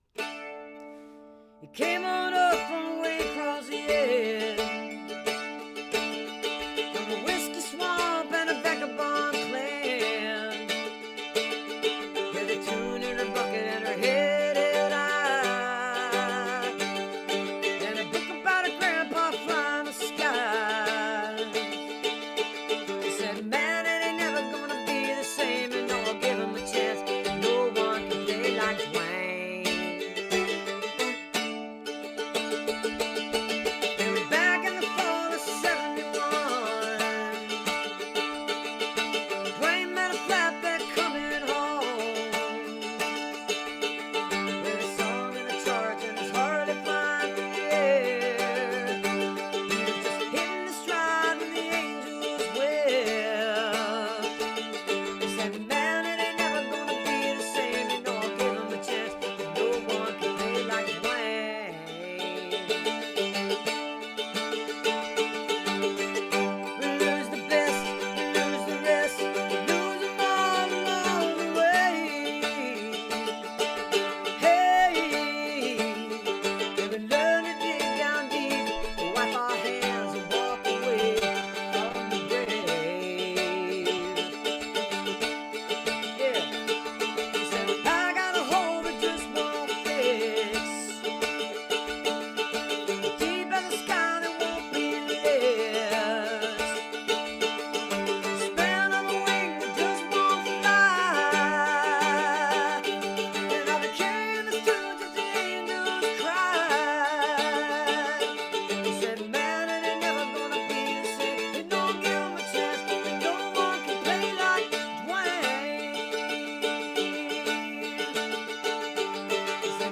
(audio from a portion of the program captured from webcast)